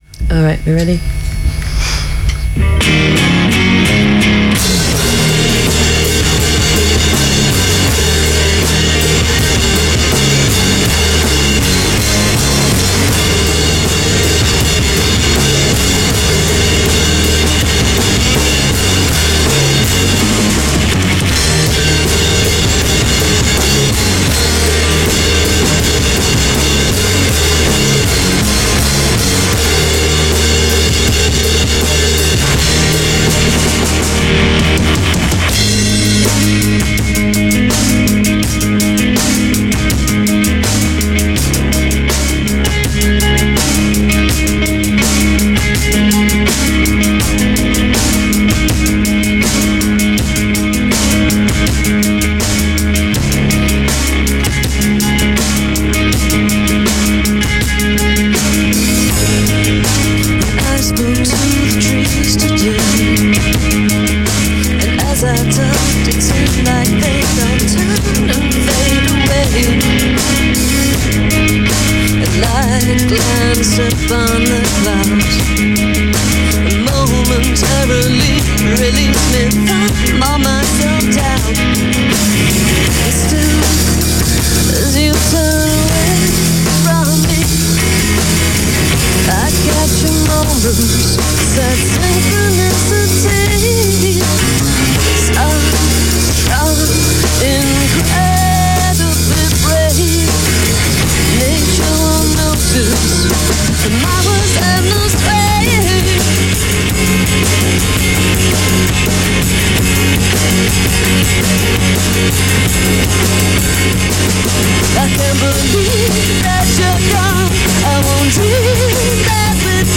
sonorous voice
Three piece power trio